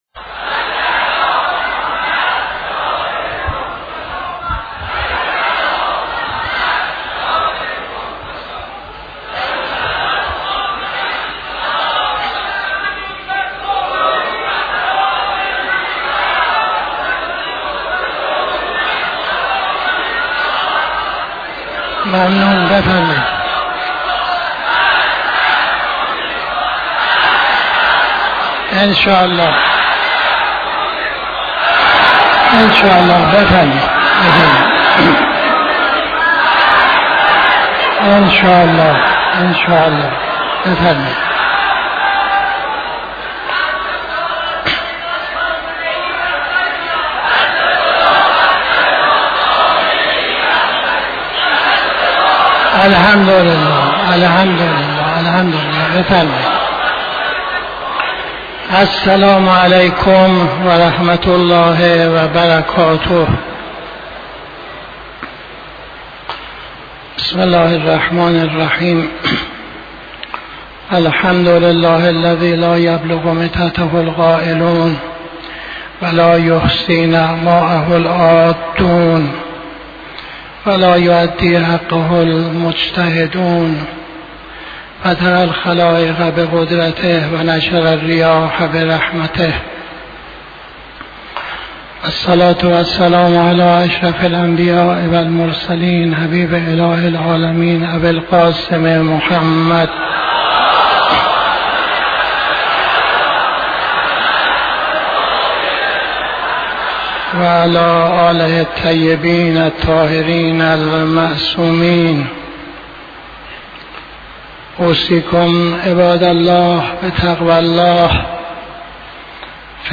خطبه اول نماز جمعه 27-04-82